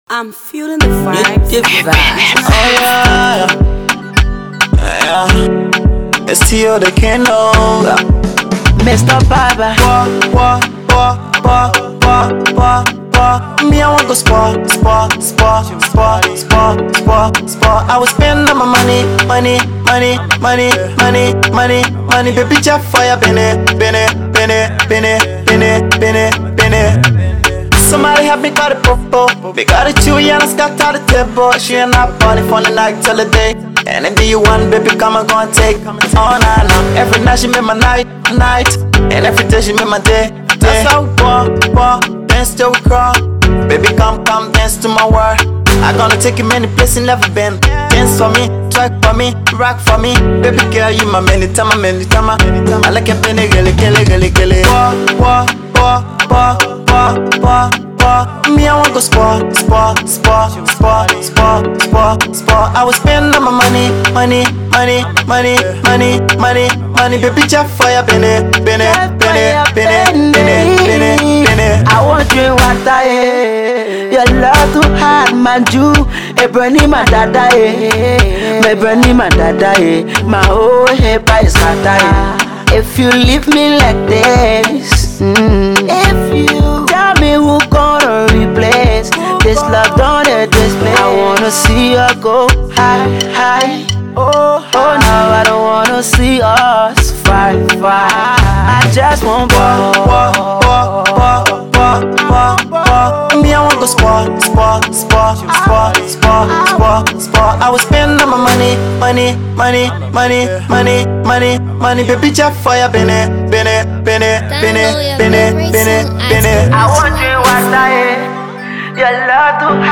fresh afrobeat sound